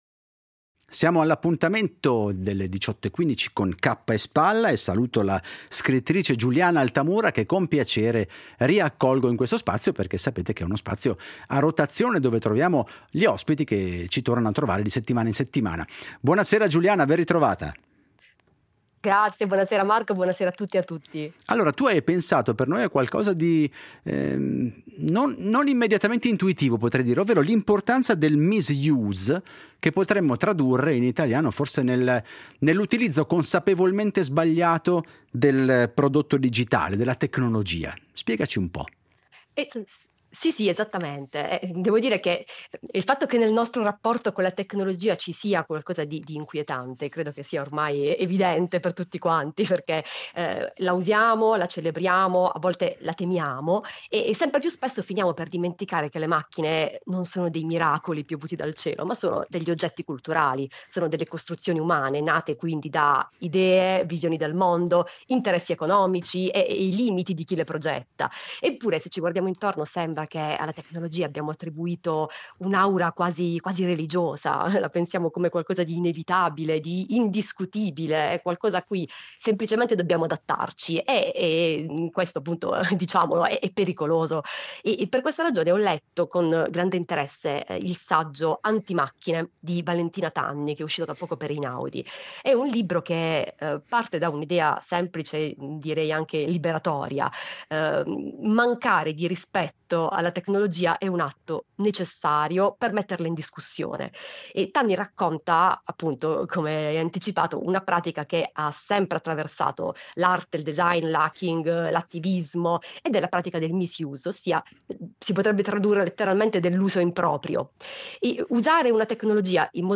Editoriali tra cultura, attualità e sguardi sul costume